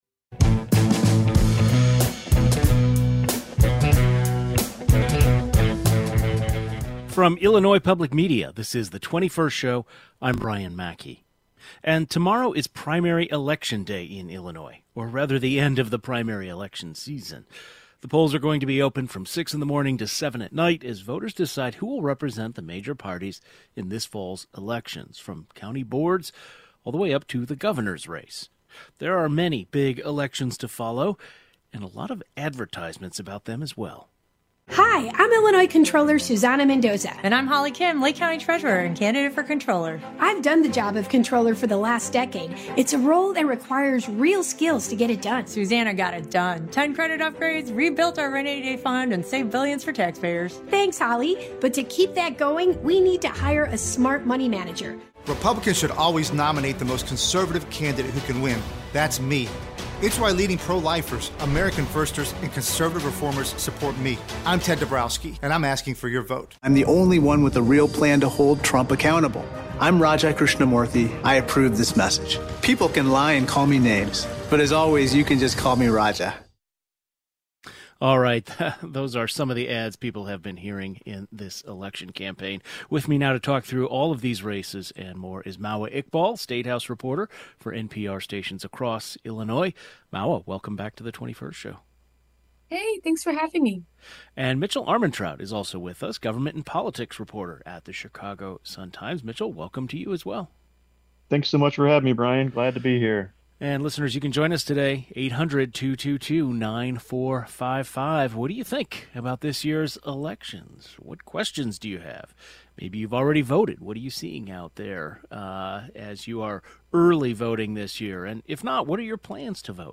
Two journalists covering local politics and the statehouse outline the keys races and candidates.